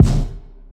stackerino_kick.wav